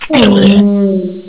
SOUNDS: Add Hellhound Sounds